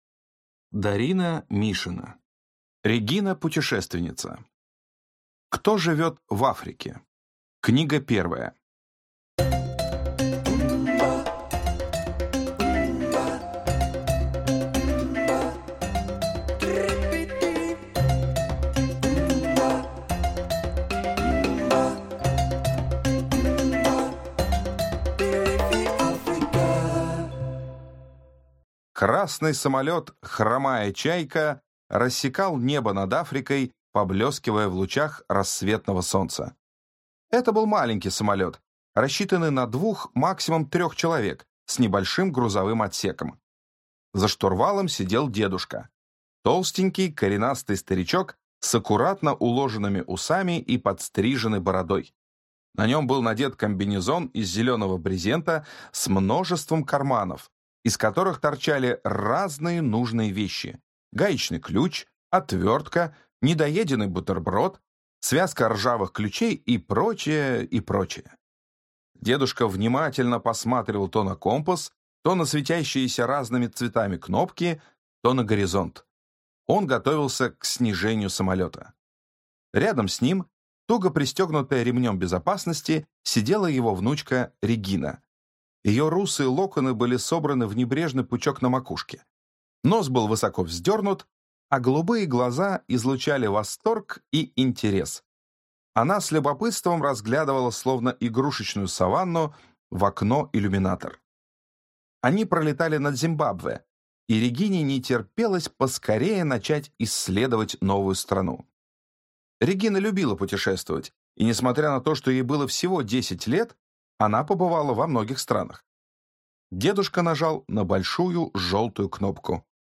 Аудиокнига Регина-путешественница. Приключения в Африке | Библиотека аудиокниг
Прослушать и бесплатно скачать фрагмент аудиокниги